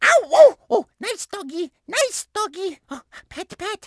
Description An unused voice clip, which appears in the Luigi's Mansion remake, which Luigi uses to talk to Spooky the dog whilst in the Boneyard.